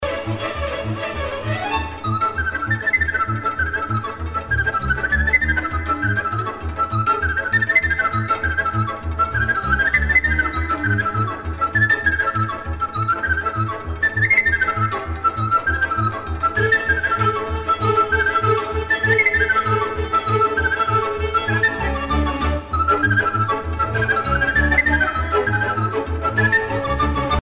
danse : brîul (Roumanie)
Pièce musicale éditée